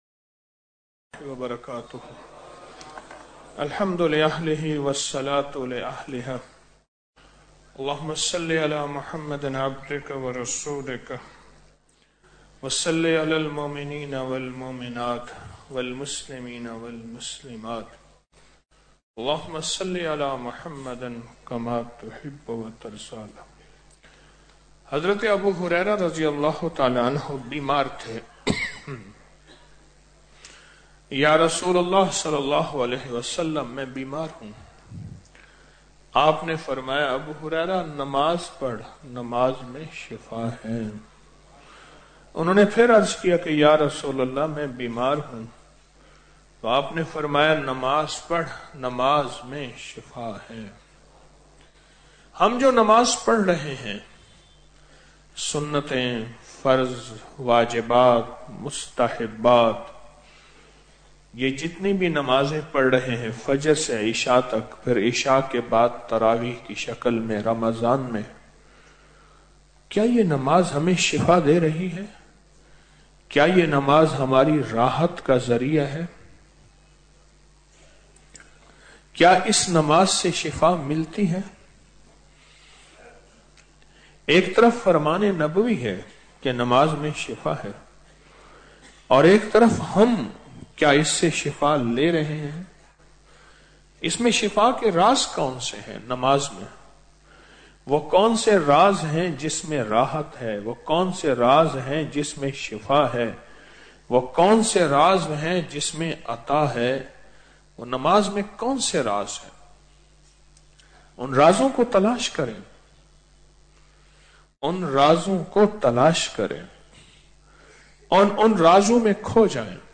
Audio Speech - 09 Ramadan After Salat Ul Taraveeh - 09 March 2025